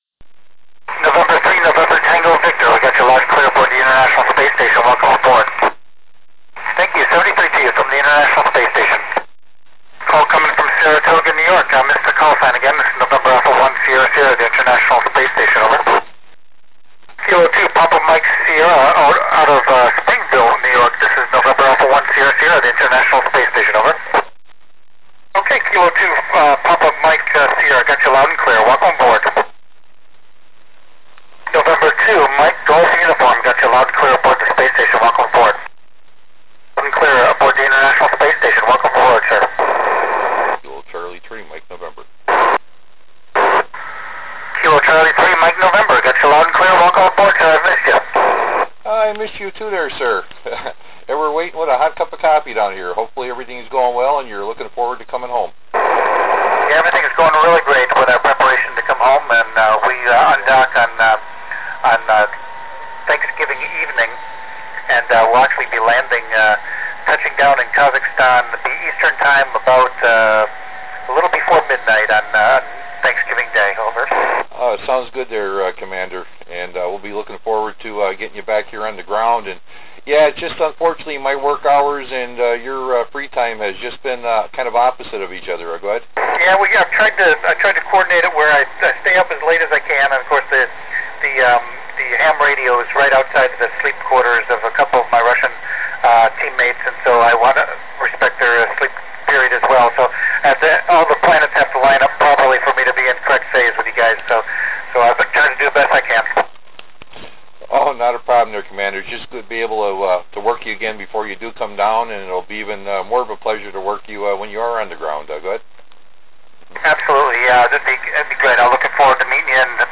Col. Doug Wheelock (NA1SS) works U.S. stations on 21 November 2010 at 17:48 UTC